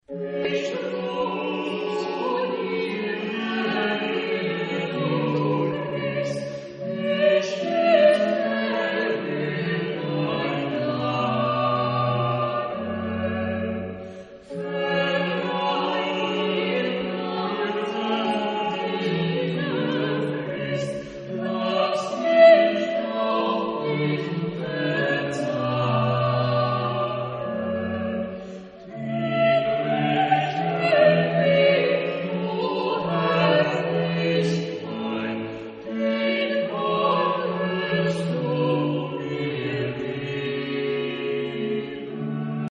Genre-Style-Form: Sacred ; Cantata ; Baroque
Type of Choir: SATB  (4 mixed voices )
Soloist(s): Soprano (1) / Alto (1) / Ténor (1) / Basse (1)  (3 soloist(s))
Instrumentation: Baroque orchestra  (9 instrumental part(s))
Instruments: Oboe (2) ; Oboe da caccia (1) ; Bassoon (1) ; Violin solo (1) ; Violin (2) ; Viola (1) ; Basso continuo